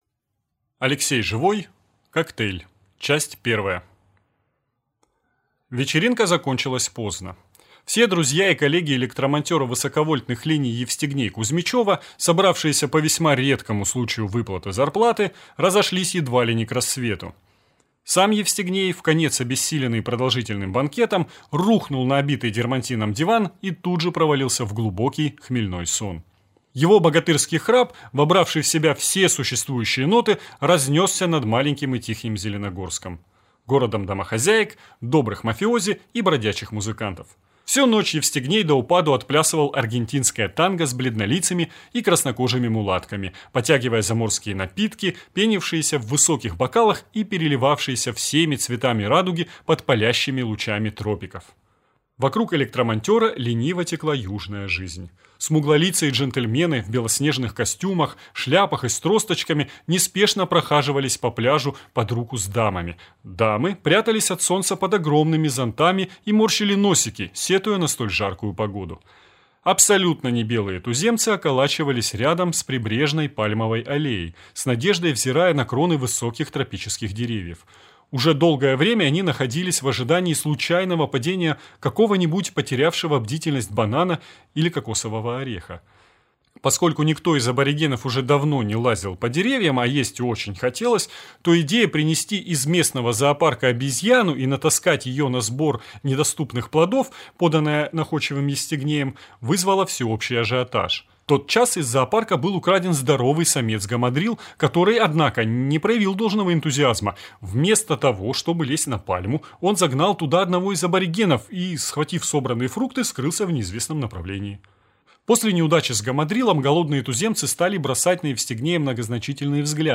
Аудиокнига Коктейль | Библиотека аудиокниг